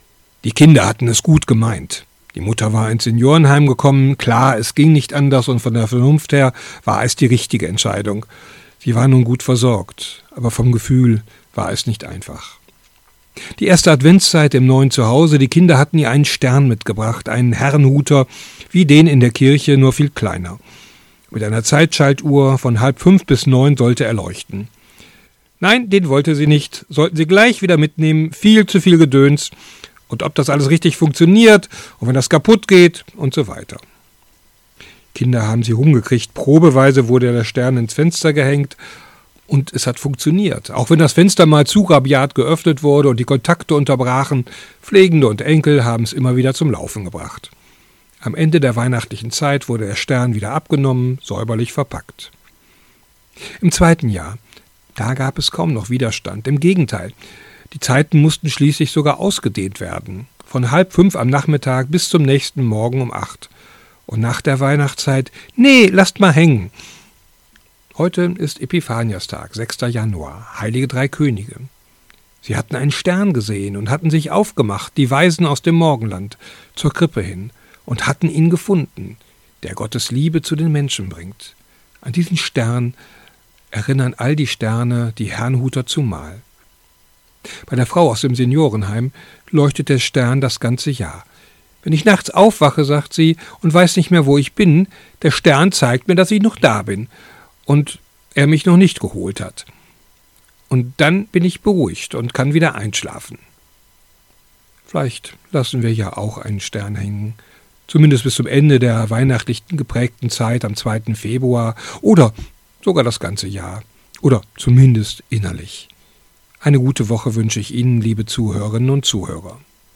Radioandacht vom 6. Januar